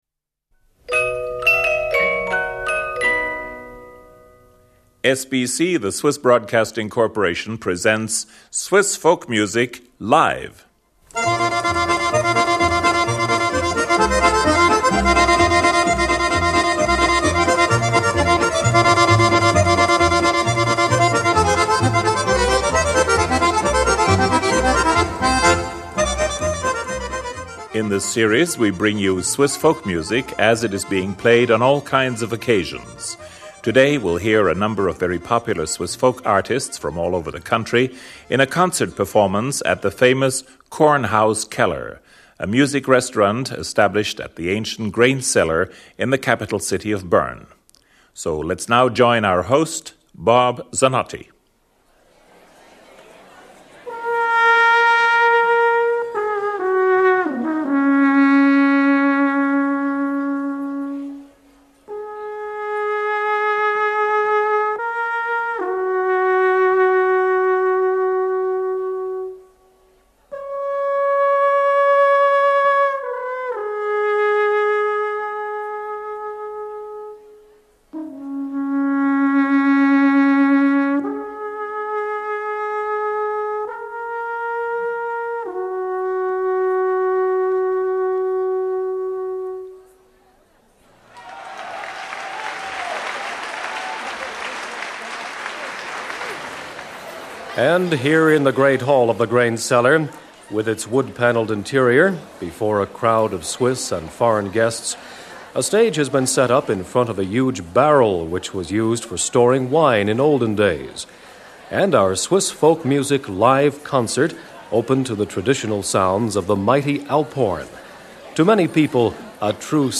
Swiss Folk Music - Live! At the Kornhauskeller of Bern.